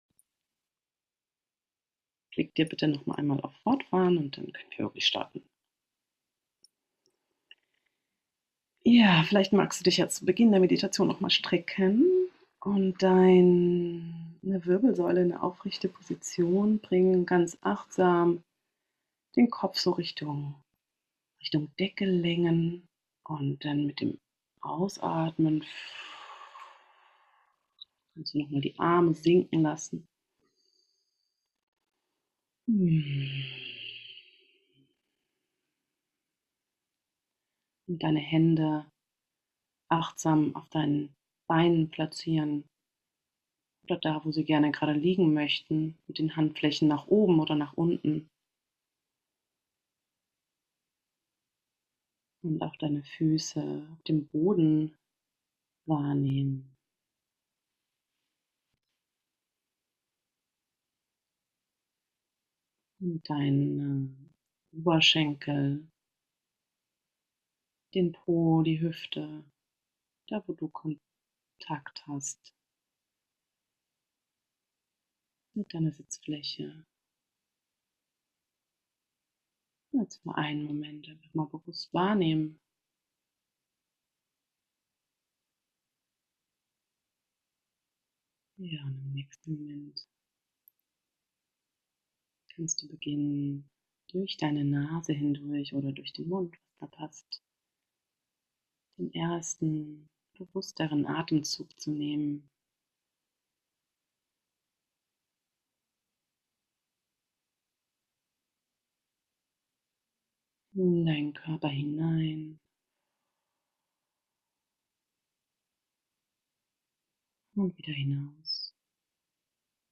In unserer Integrationszeit haben wir mithilfe einer geführten Meditation den Bildungsgipfel Revue passieren lassen.